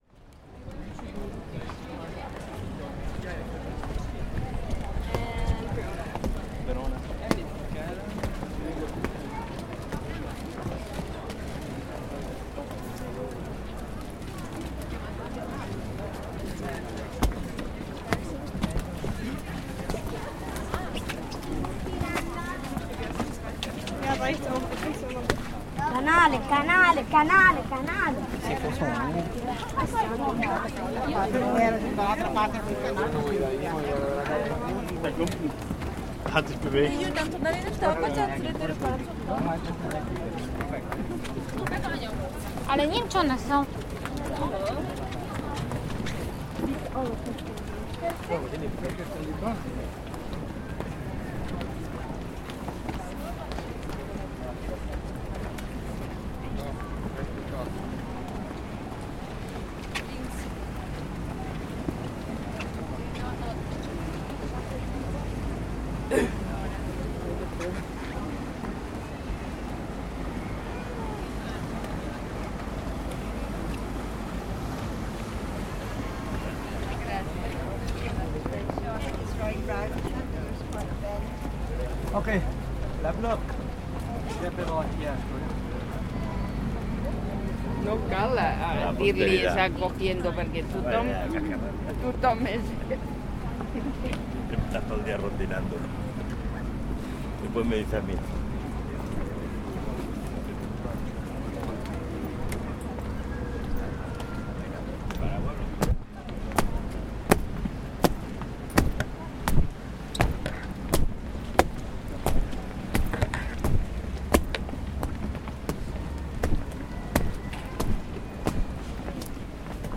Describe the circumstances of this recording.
Walking across the Ponte dell'Accademia in Venice on a rainy September afternoon.